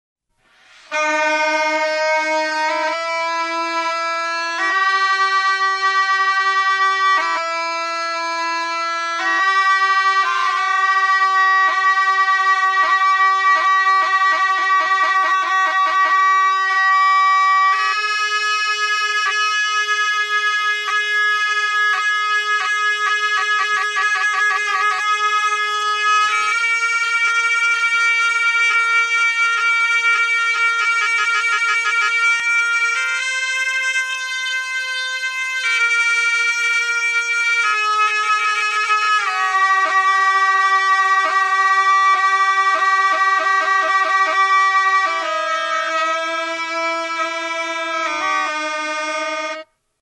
Aerófonos -> Lengüetas -> Doble (oboe)
Sahnai bikote baten joaldia.
ASIA -> INDIA
Mihi bikoitzeko soinu-tresna da.